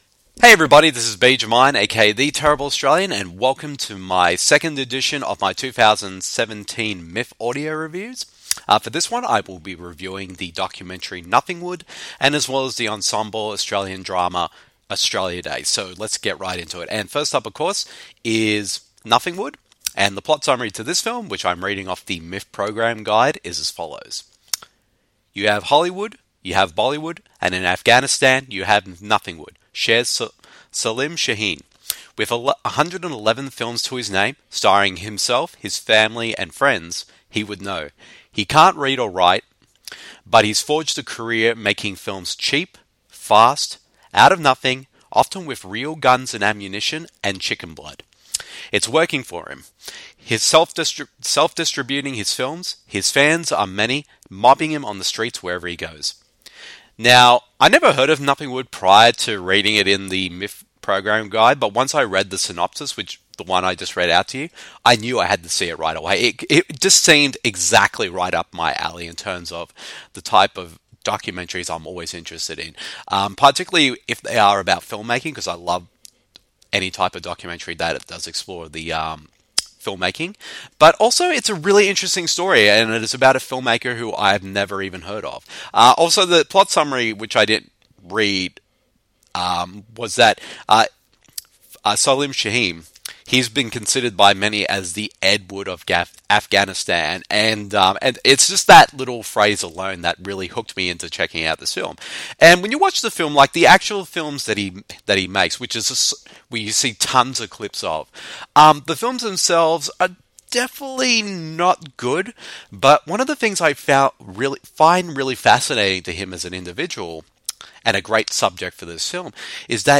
Welcome to the 2nd edition of my 2017 Melbourne International Film Festival (a.k.a. MIFF) audio reviews. In this one, I share my thoughts on the filmmaking themed documentary NOTHINGWOOD and RED DOG director Kriv Stenders topically themed ensemble Aussie drama AUSTRALIA DAY.